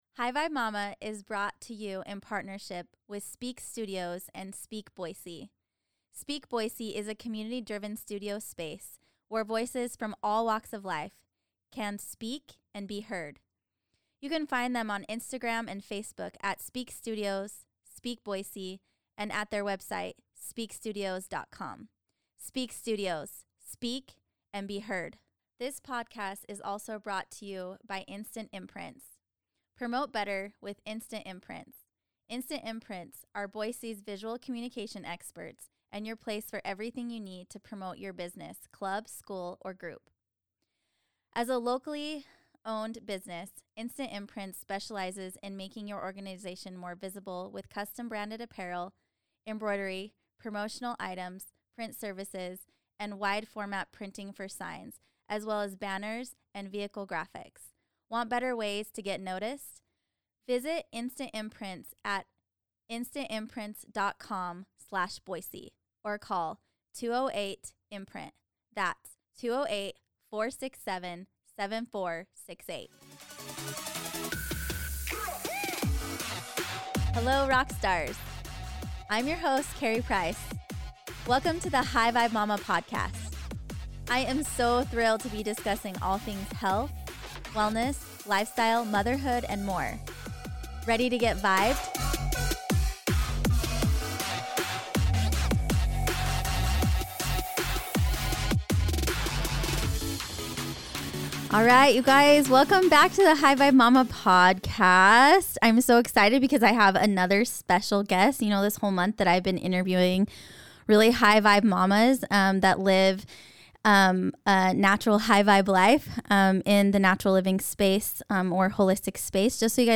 #10 LIVING AN EMPOWERED LIFE, INTERVIEW W